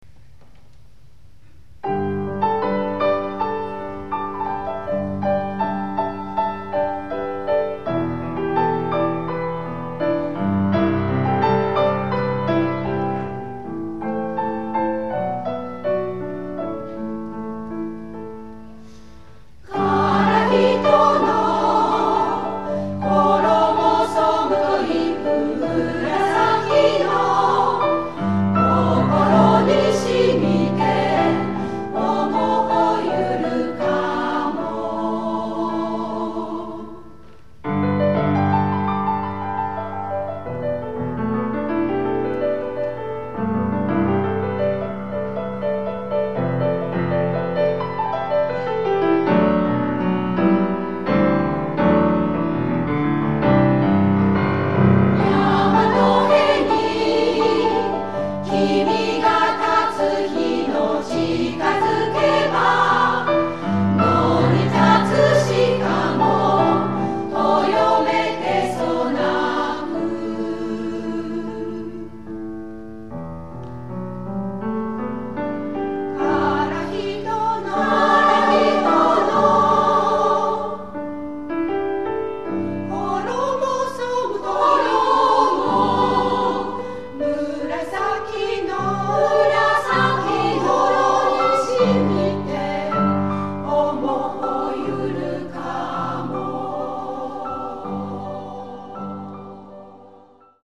女声合唱団「道」 お知らせ
女声合唱団「道」の第３回コンサートを平成１９年６月１６日（土）に開きました。